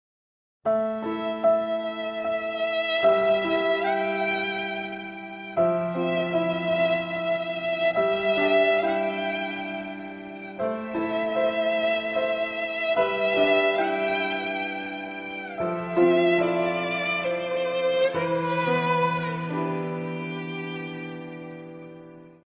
Грустная мелодия на скрипке из мема неудача